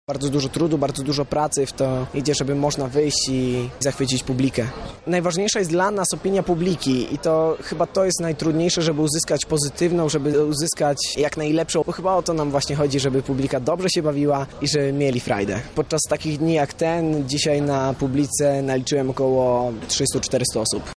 grupy zajmującej się artystycznymi pokazami ognia.